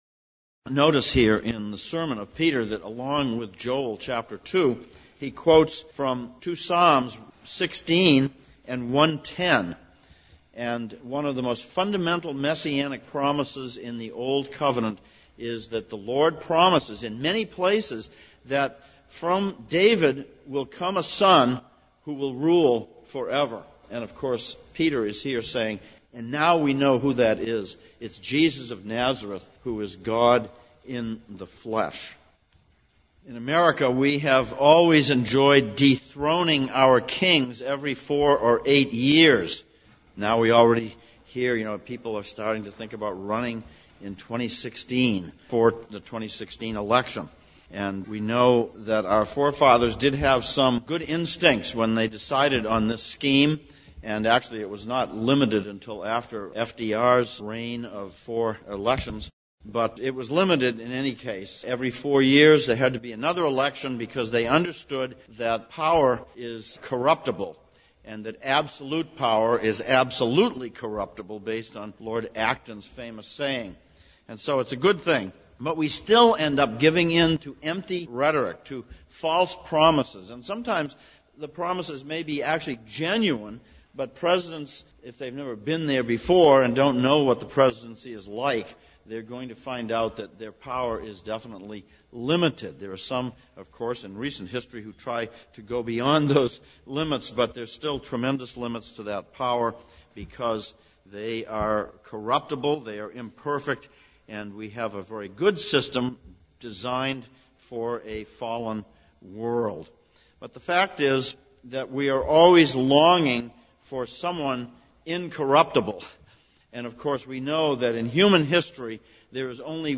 Psalms of Ascents Passage: Psalm 132:1-18, Acts 2:14-36 Service Type: Sunday Morning « 15.